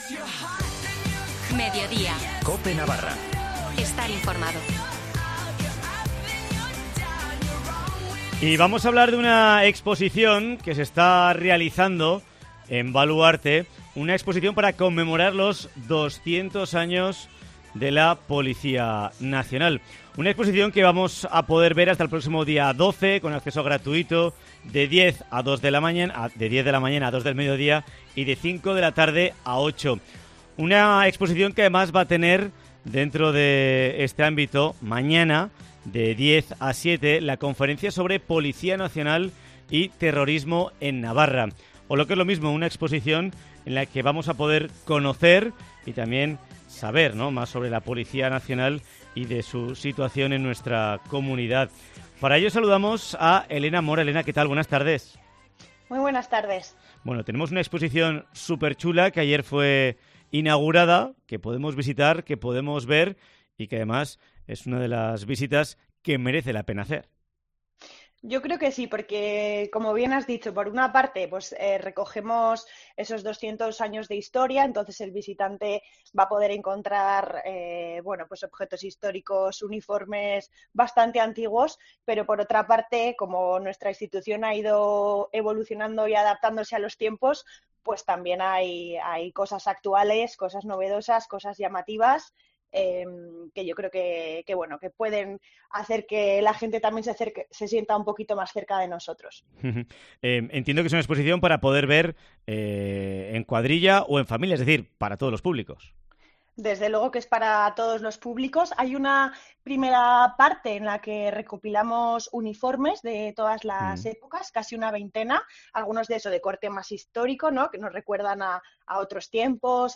Entrevista con la Inspectora de Policía Nacional